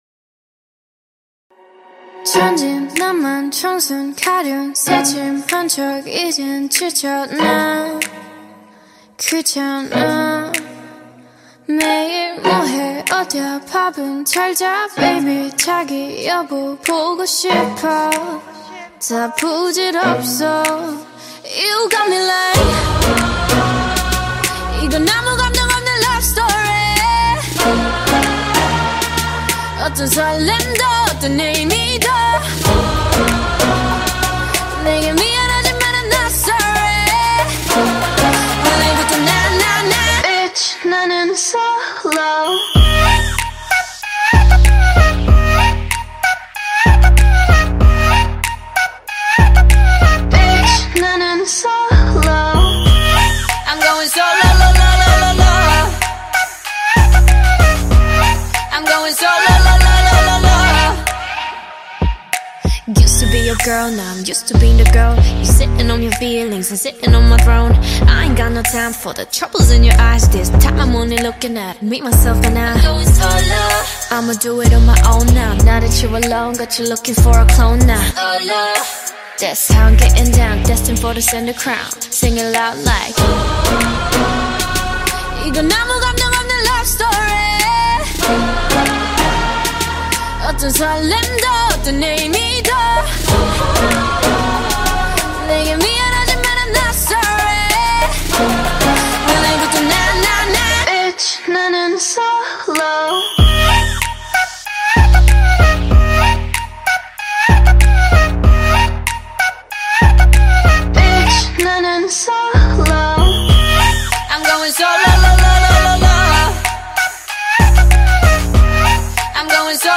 ژانر: K-pop